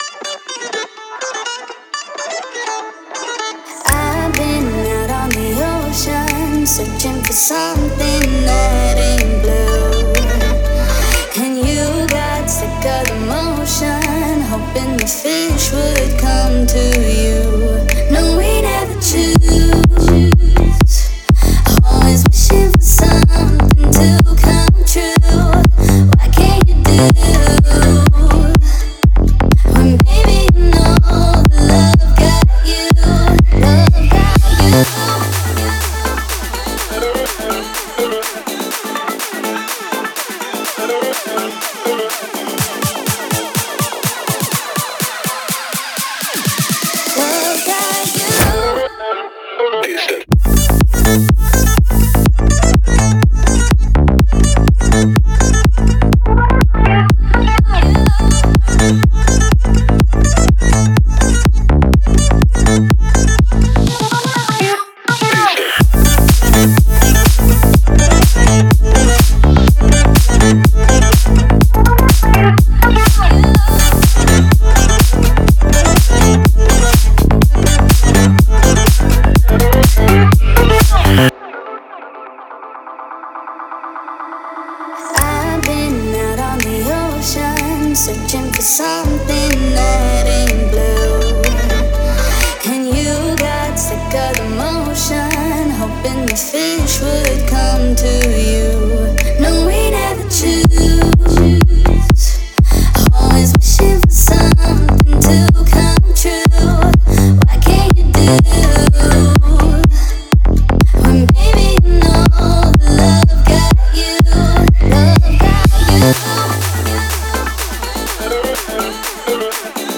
это динамичная и эмоциональная композиция в жанре поп
с ярким вокалом и энергичными ритмами